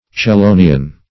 Chelonian \Che*lo"ni*an\, a. (Zool.)